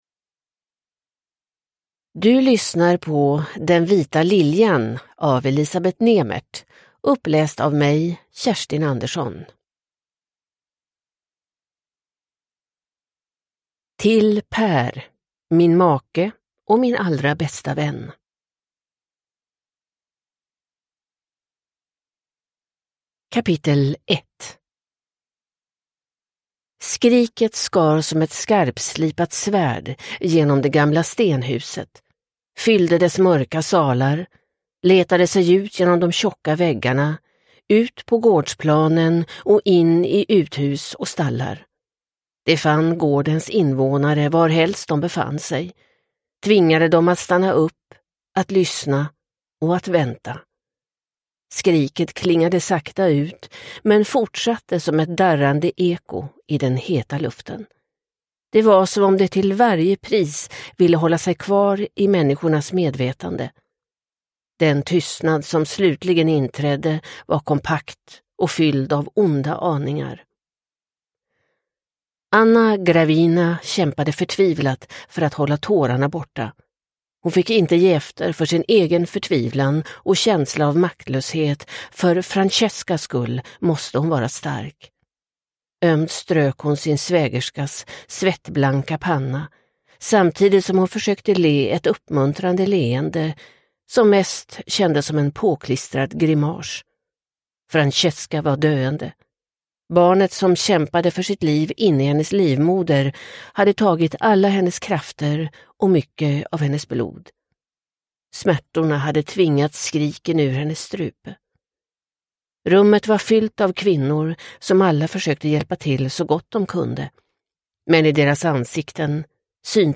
Den vita liljan – Ljudbok – Laddas ner